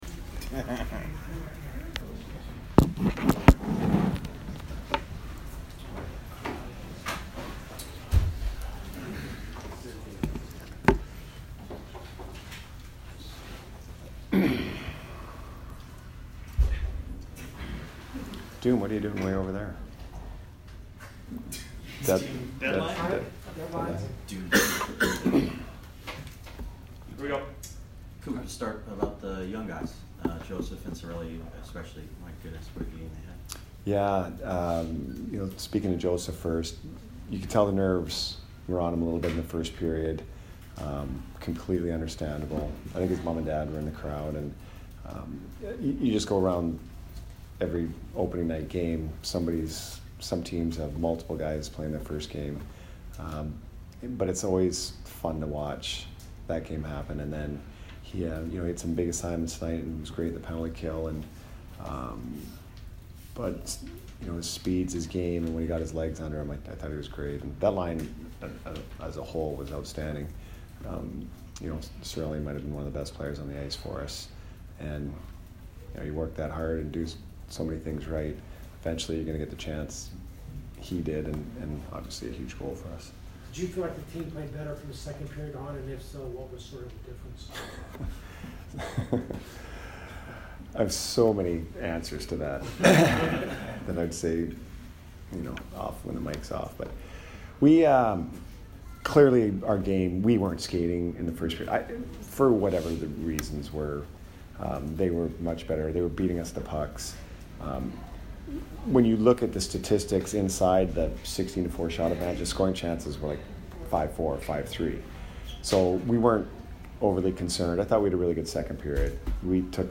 Jon Cooper post-game 10/6